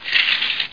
1 channel
00812_Sound_curtain.mp3